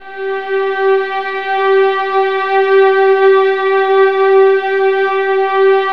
VIOLINS AN4.wav